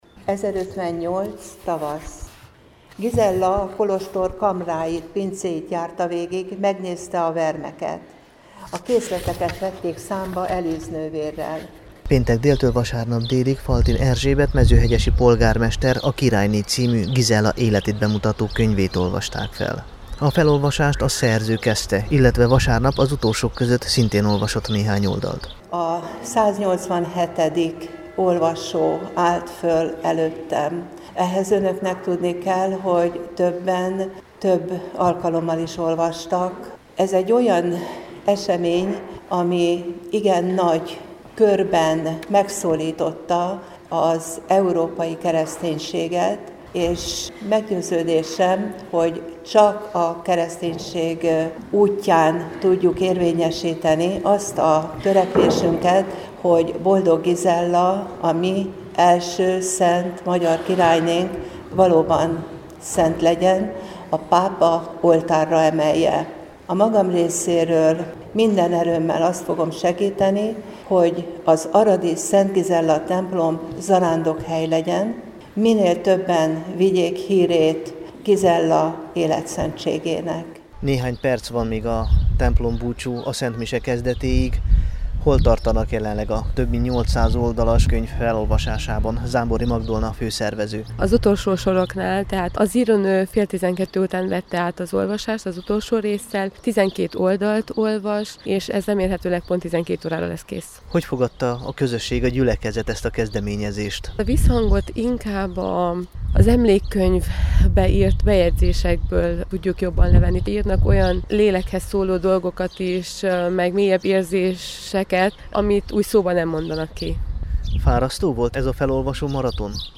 Befejezodott_a_maratoni_felolvasas_Gajban.mp3